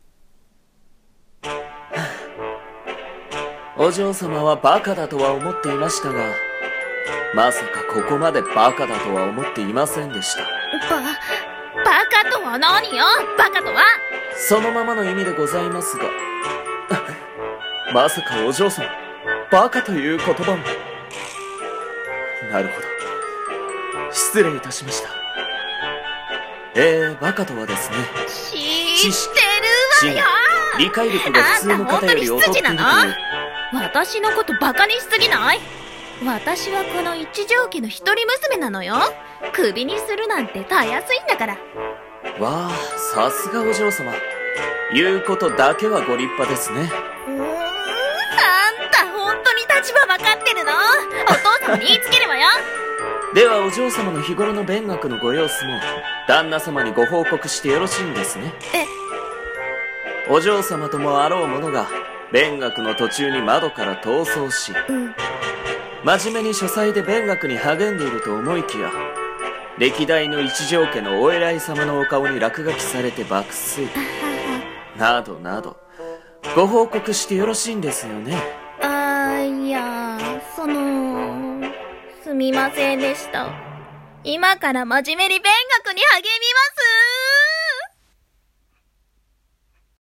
【声劇】 バカなお嬢様【掛け合い】